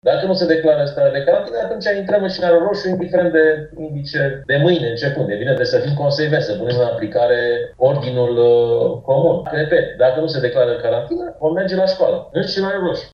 Decizia se menține, cel puţin, până la vacanţa de primăvară, spune șeful Inspectoratului Școlar Timiș, Marin  Popescu.